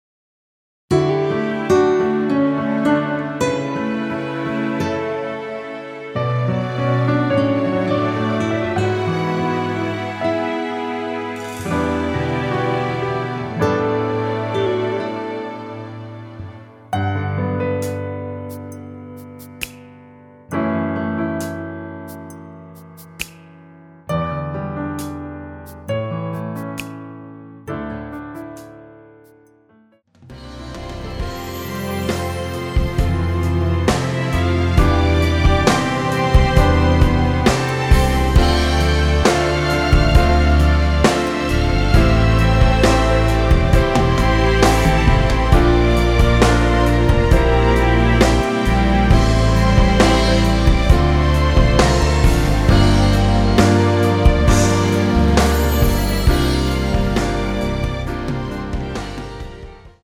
(-2) 내린 MR 입니다.(미리듣기 참조)
앞부분30초, 뒷부분30초씩 편집해서 올려 드리고 있습니다.